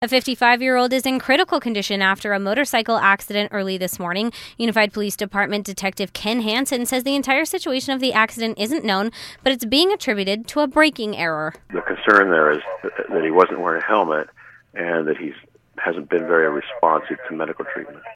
reports how a 55-year-old man is in critical condition after crashing on his motorcycle. He was not wearing a helmet.